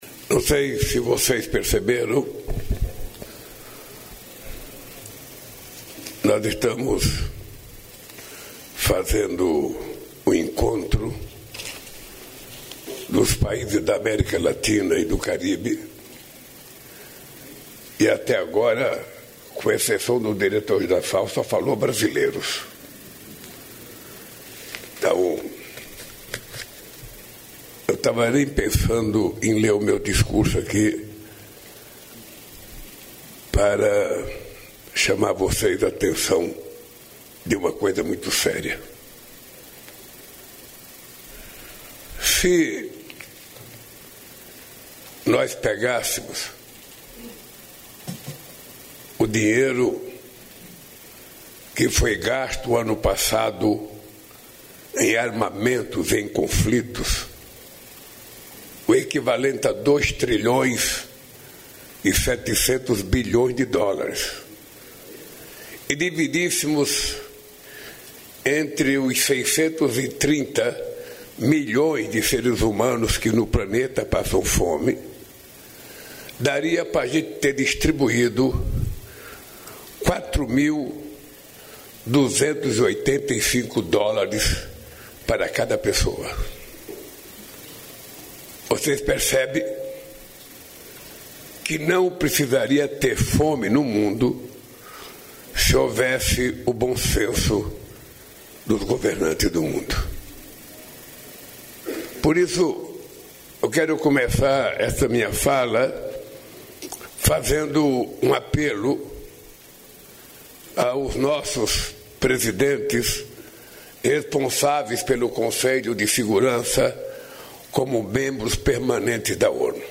Íntegra do discurso do presidente Luiz Inácio Lula da Silva na cerimônia de entrega da medalha Oswaldo Cruz, nesta quarta-feira (11), no Palácio do Planalto, em Brasília.